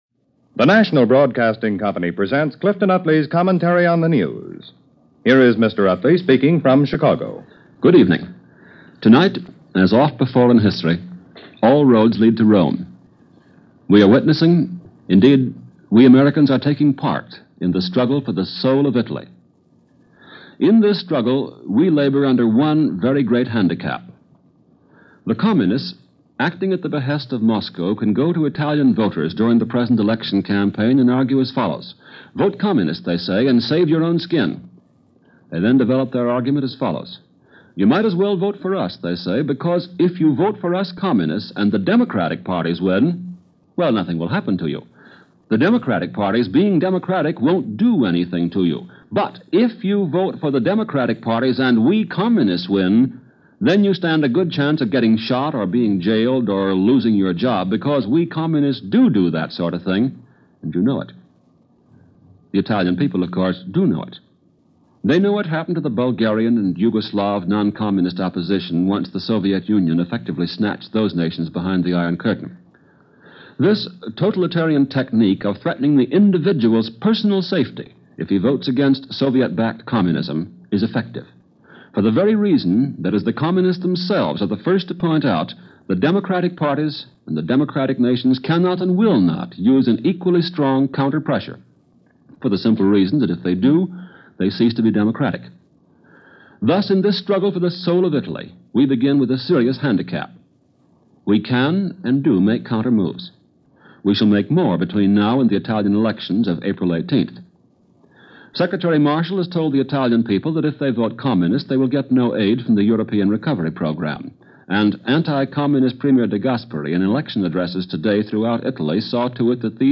Italy Gets Ready To Vote - Combustible Political Winds - March 21, 1948 - Clifton Utley News and Commentary - NBC Radio